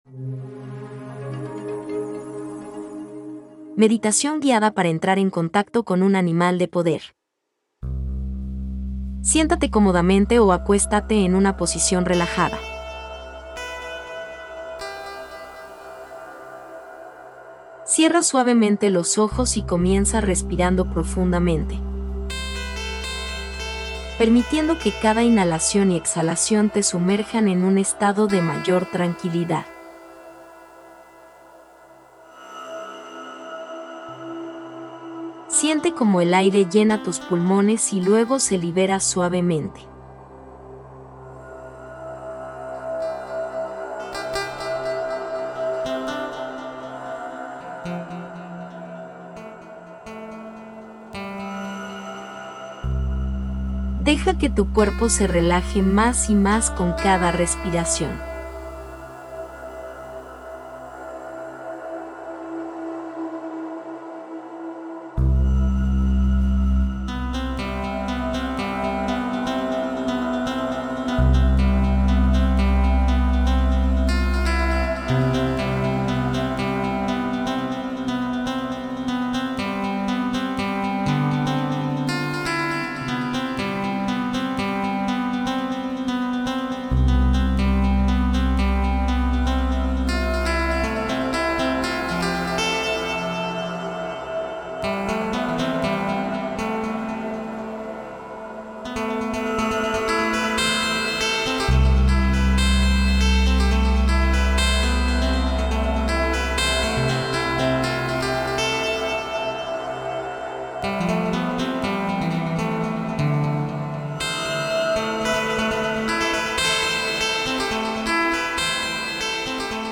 MEDITACIÓN GUIADA